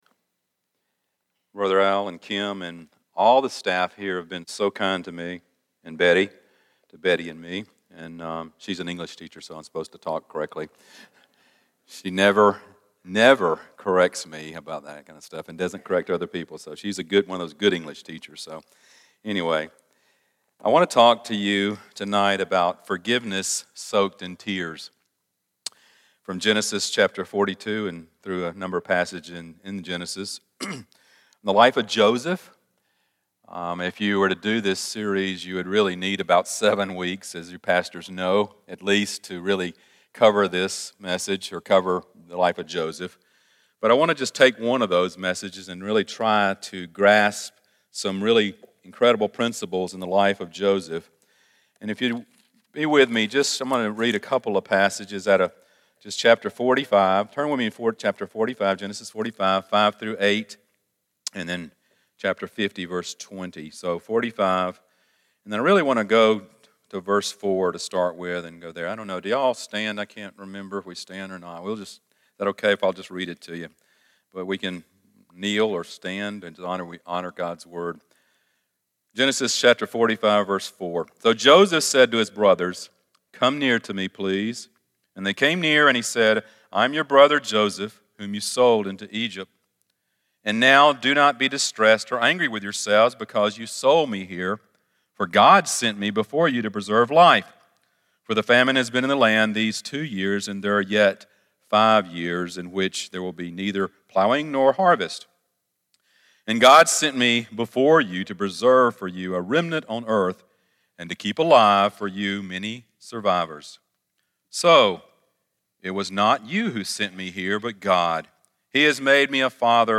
Stand Alone Sermons
Service Type: Sunday Evening